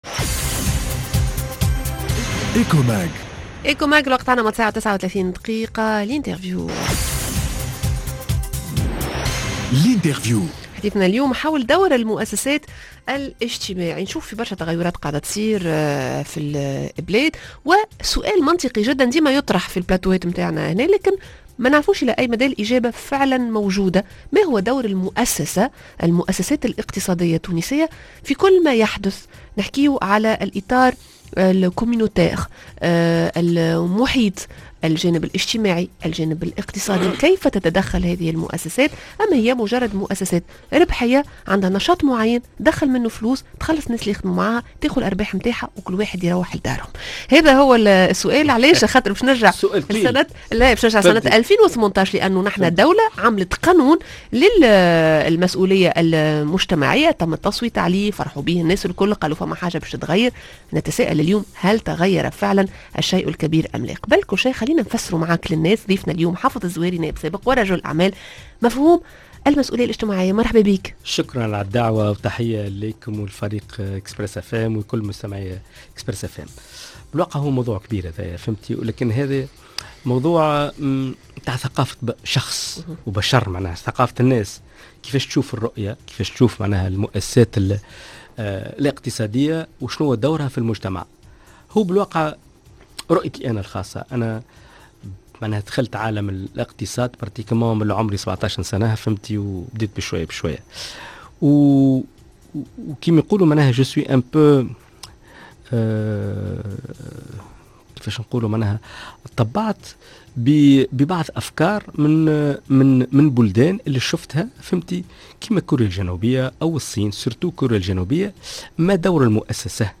L'interview: المؤسسات لازم تلعب دورها الاجتماعي اليوم.